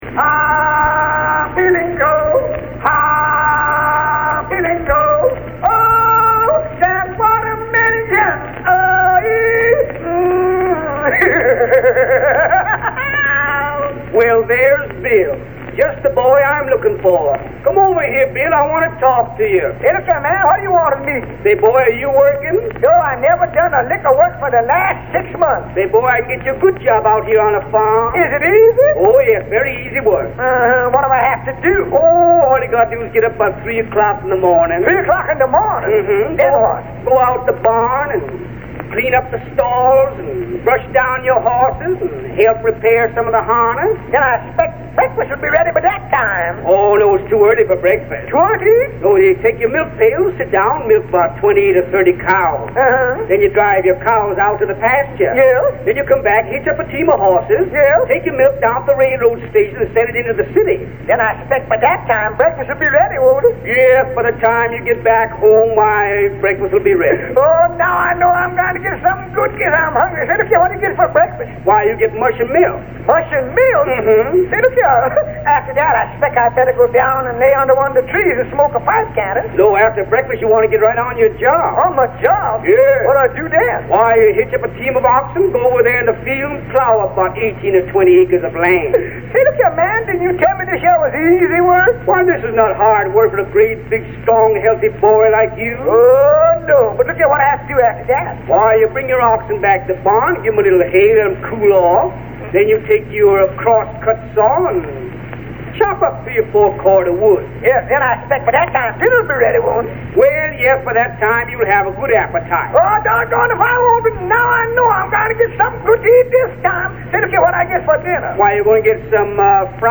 It is "Comic Minstrel Routines" that includes the one track I wanted,